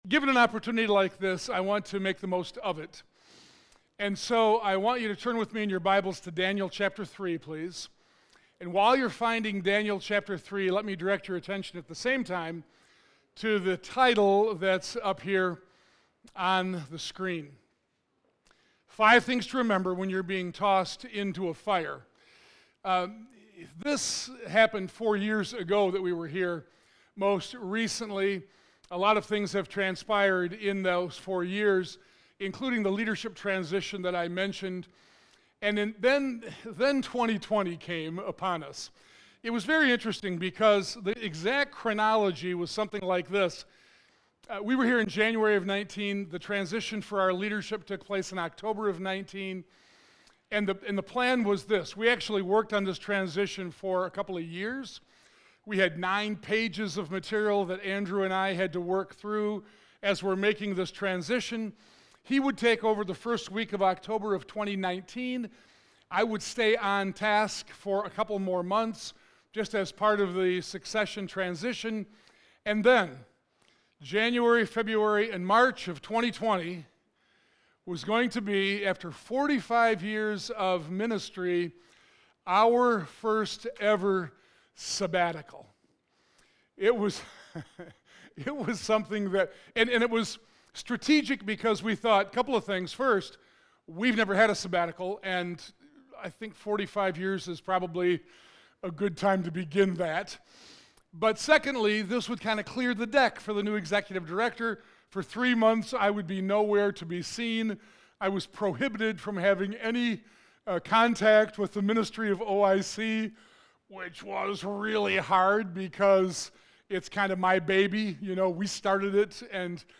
Topical Sermon